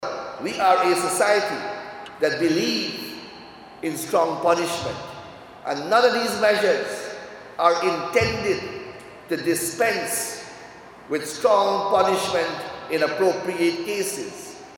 Attorney General and Minister of Legal Affairs, Anil Nandlall, announced the government’s initiative to implement alternative means of dispute resolution during the opening of a restorative justice practitioners training.